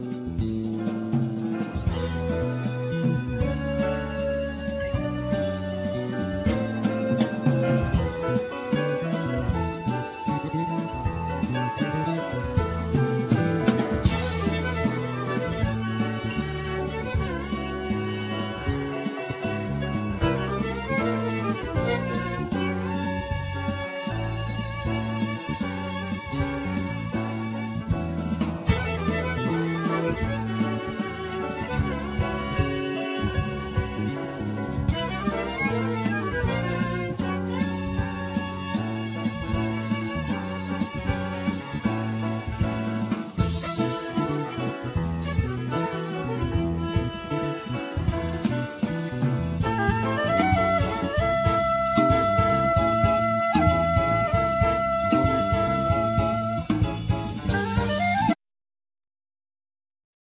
Violin
Keyboards
Drums
Bass
El.guitar
Sopranosaxophne